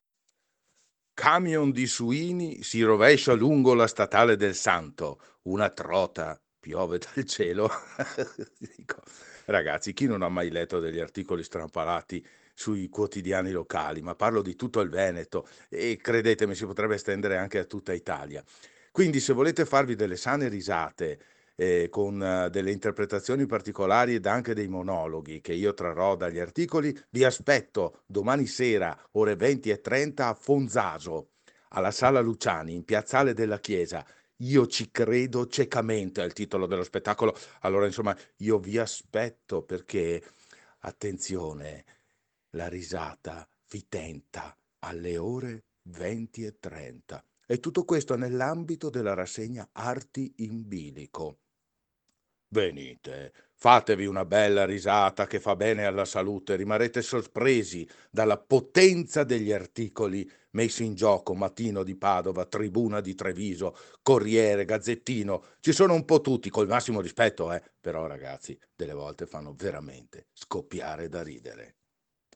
IERI ALLA RADIO… PRESENTA L’APPUNTAMENTO DI OGGI